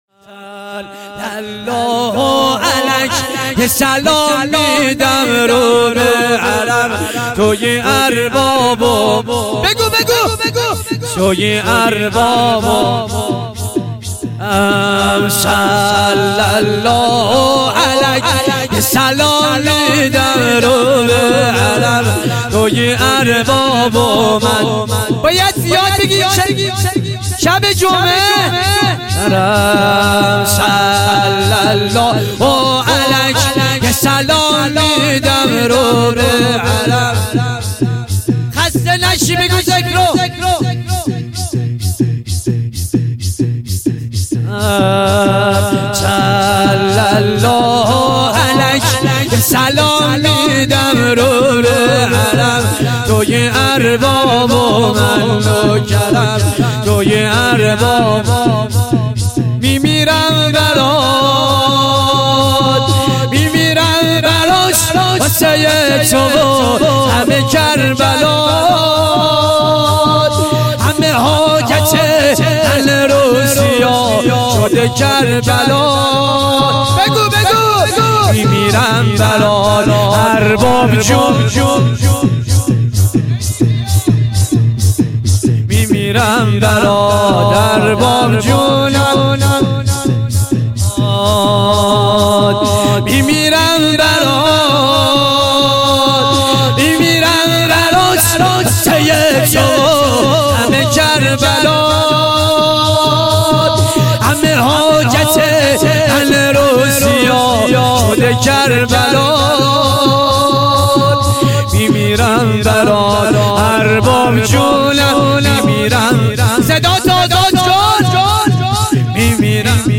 مراسم هفتگی
شور